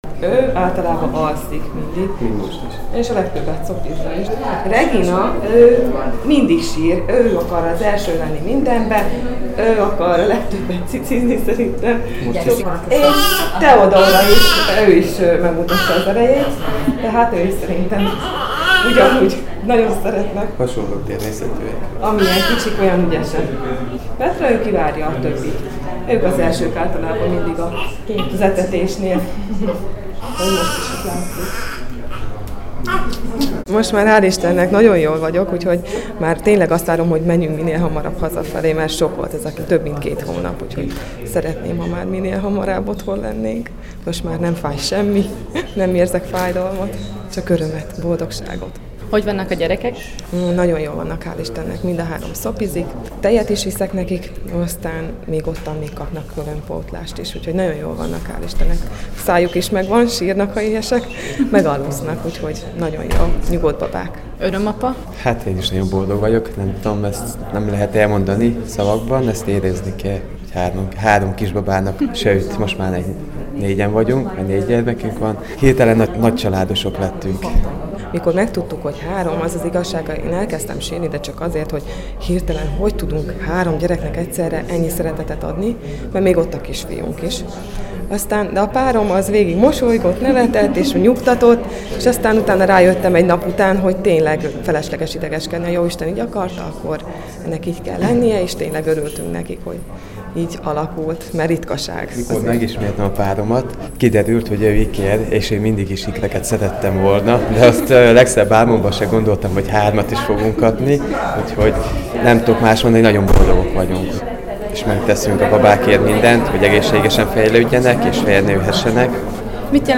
A kórházban készített riportott itt hallhatják.